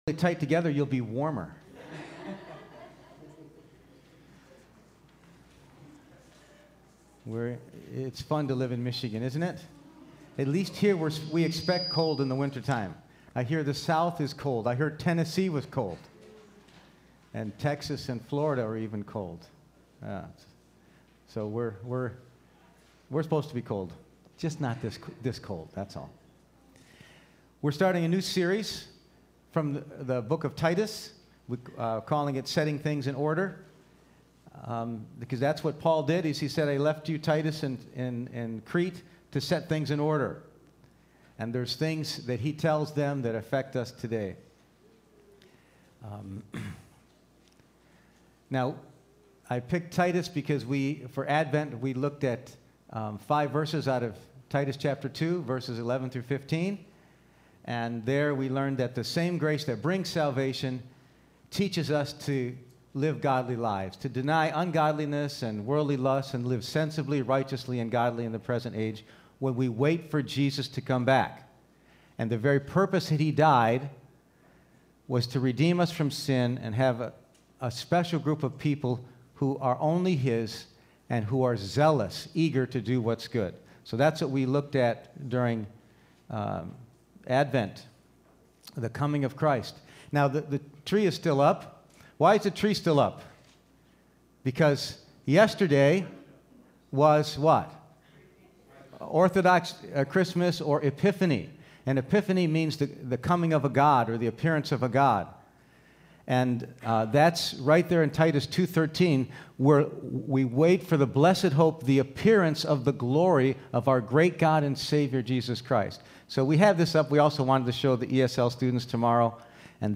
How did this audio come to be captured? Titus 1:1-4 Service Type: Sunday Morning %todo_render% « New Year 2018